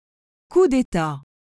🎧 Coup d’État pronunciation
ku de.ta/, which sounds like koo deh-tah.